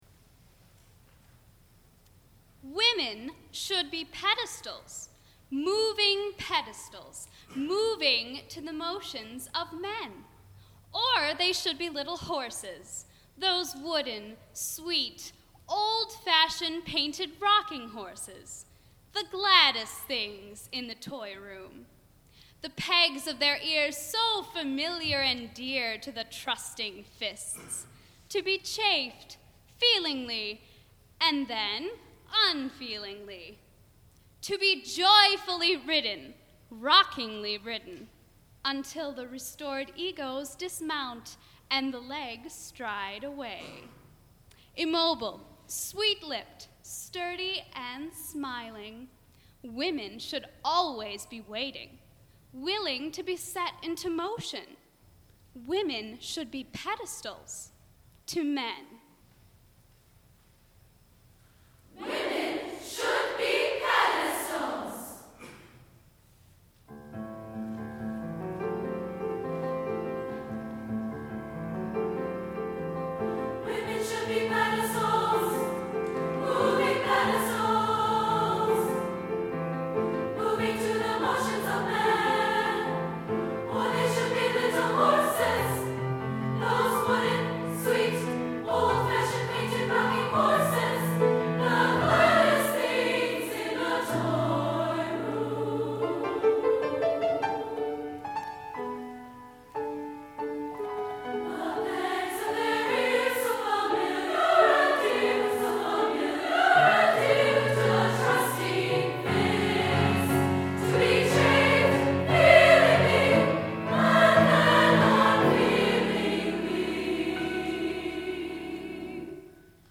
for SSAA Chorus and Piano (1993)